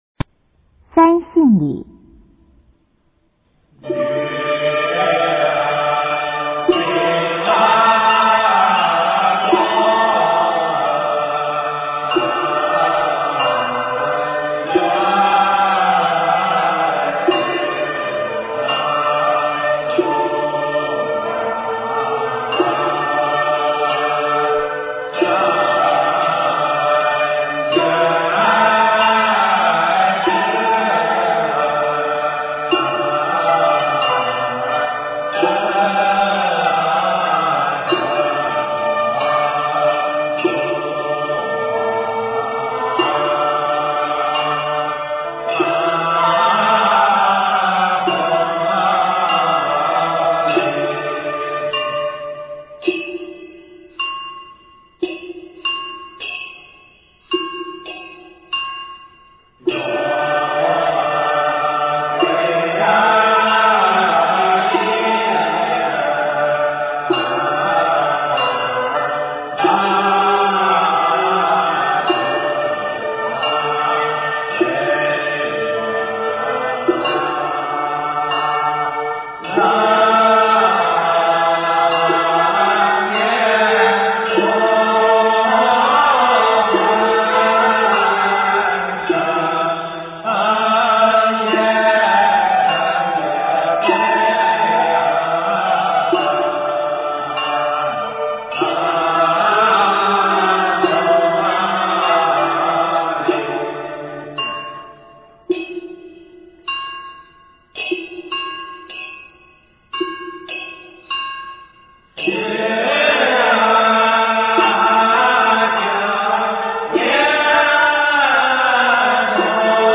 用途：三信礼为阴事所用，祈求道经师三宝，即三清上圣施惠九幽，拔度沉沦，哀挨恳求之音极为感人。